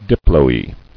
[dip·lo·e]